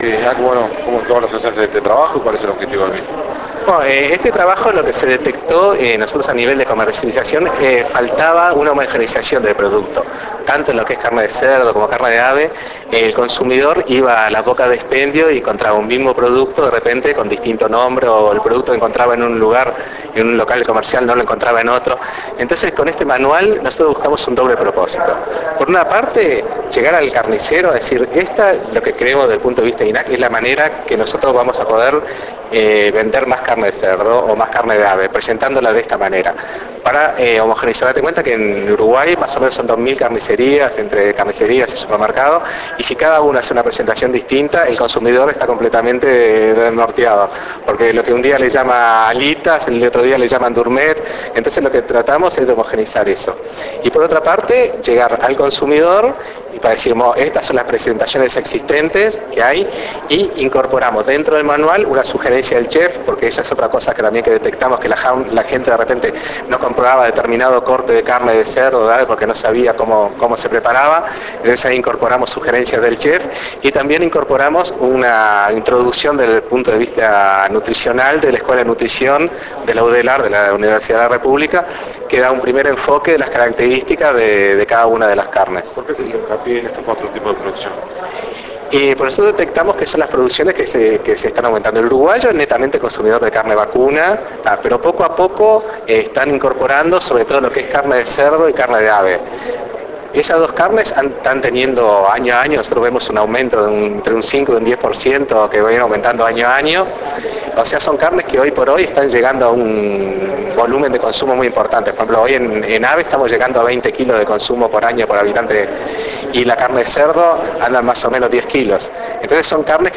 Mercado Interno  Entrevista Manual de Carnes Alternativas 3:23